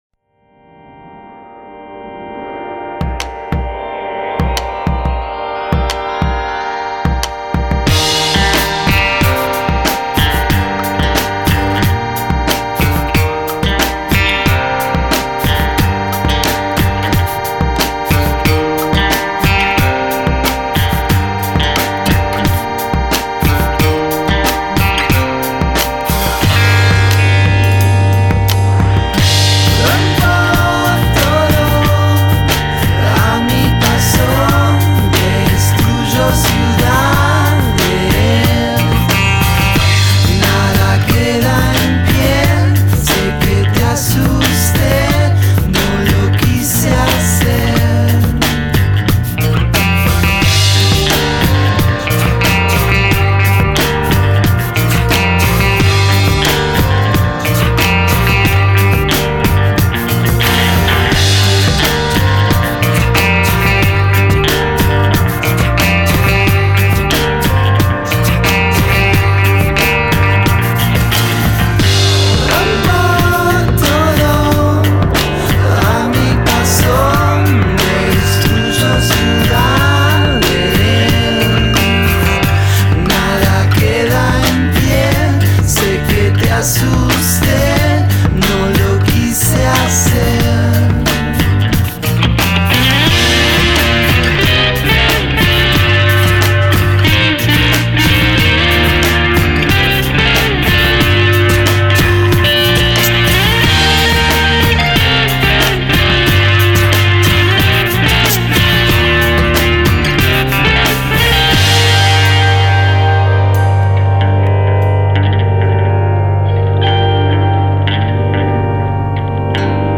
une pop colorée et ensoleillée venue tout droit d'Argentine